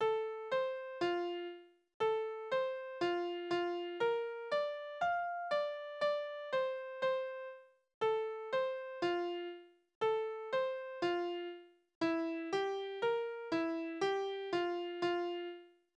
Kindertänze: Die bunte Schürze
Tonart: F-Dur
Taktart: C (4/4)
Tonumfang: kleine None
Besetzung: vokal